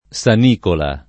sanicola [ S an & kola ]